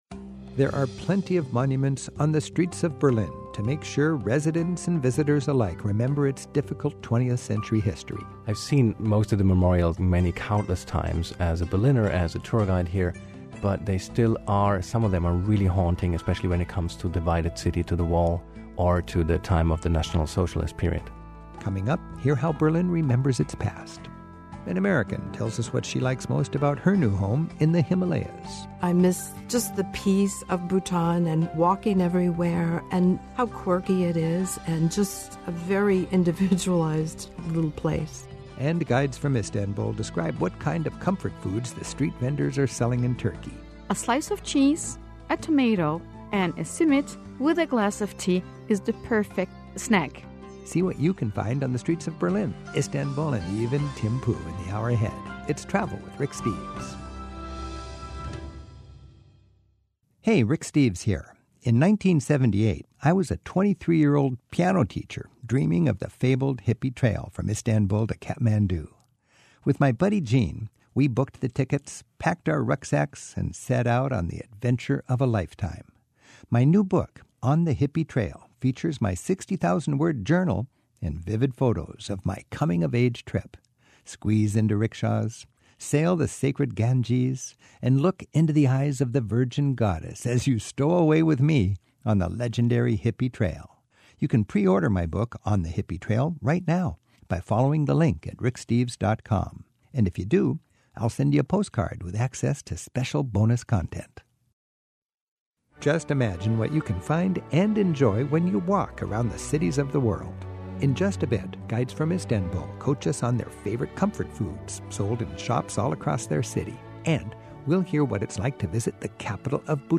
My Sentiment & Notes 602a How Berlin Remembers; Turkish Delights; Travel to Bhutan Podcast: Travel with Rick Steves Published On: Sat Jan 25 2025 Description: Two German tour guides let us in on the back story behind Berlin's most memorable monuments to their country's turbulent history. Then two Turks tuck into a discussion of the traditional Turkish street eats, comfort foods, and desserts they recommend for a tasty visit to Istanbul.